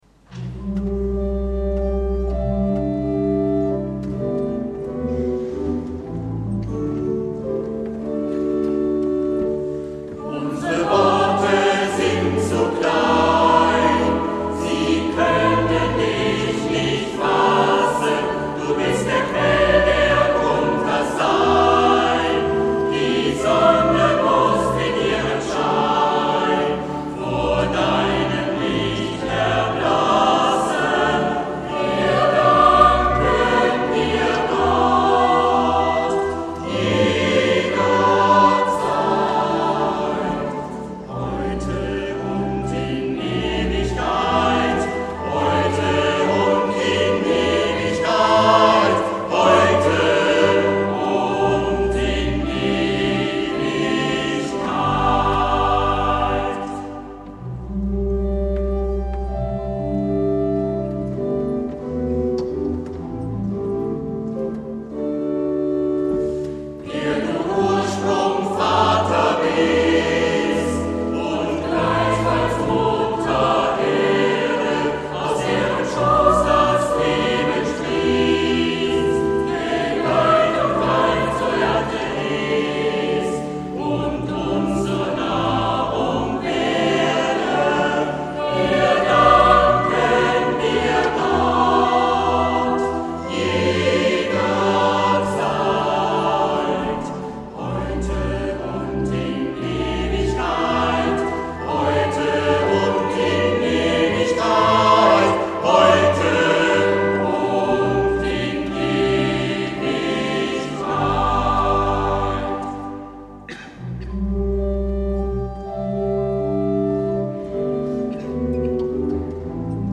Auferstehungsmesse Ostern 2011